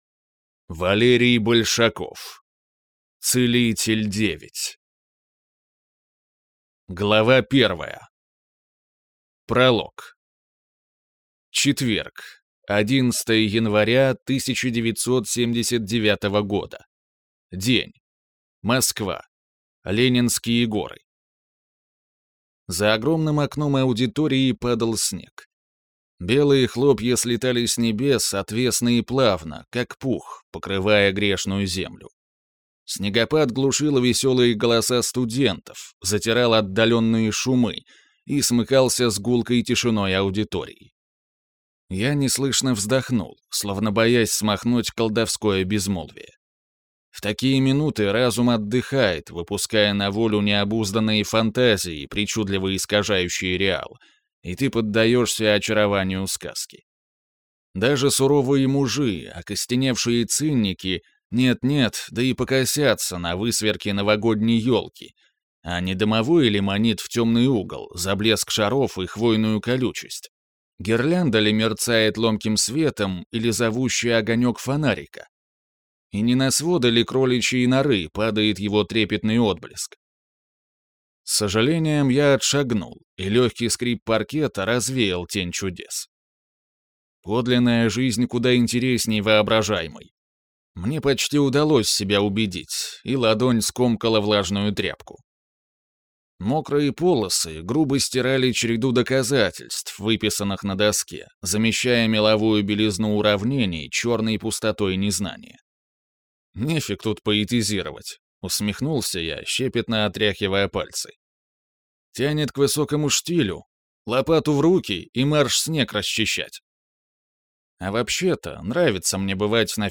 Целитель - 9. Аудиокнига